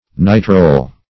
Search Result for " nitrol" : The Collaborative International Dictionary of English v.0.48: Nitrol \Ni"trol\, n. (Chem.) Any one of a series of hydrocarbons containing the nitro and the nitroso or isonitroso group united to the same carbon atom.
nitrol.mp3